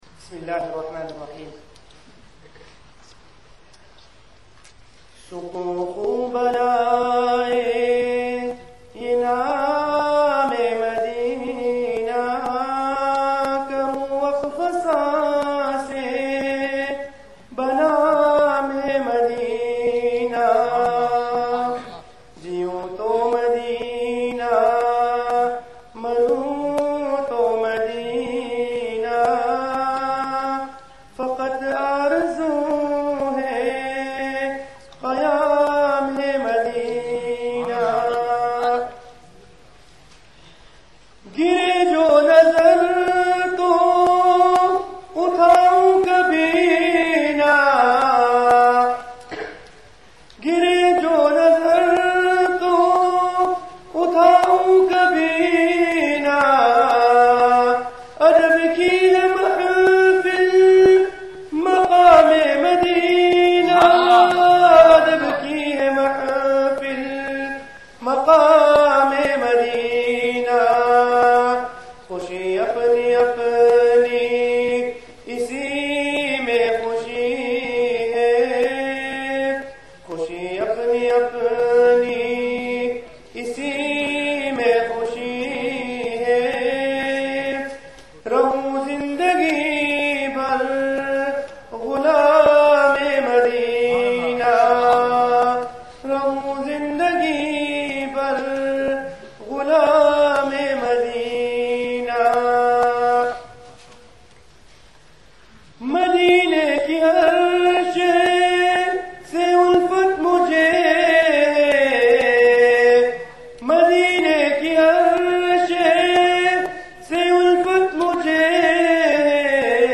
Bukhari Graduation 2008 Darul-Uloom, Madinatul-Uloom
Naat & Nasheeds